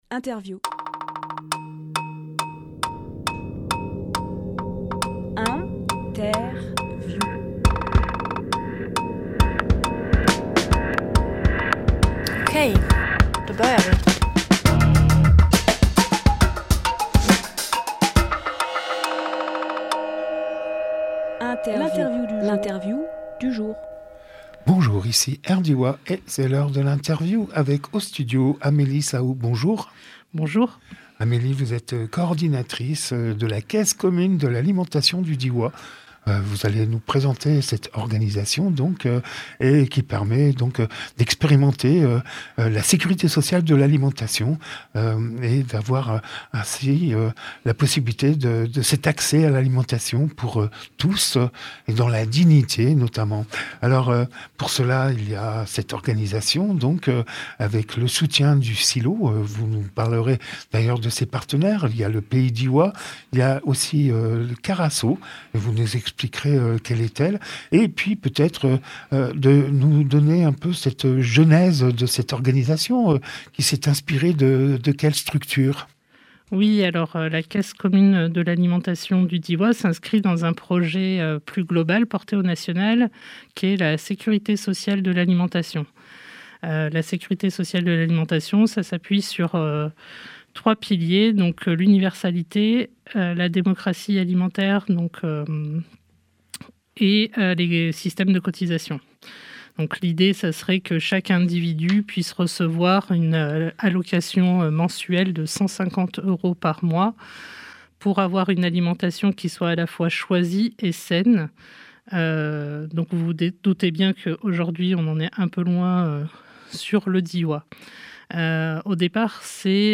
Emission - Interview Journée de la caisse commune de l’alimentation à Menglon Publié le 8 janvier 2026 Partager sur…
lieu : Studio RDWA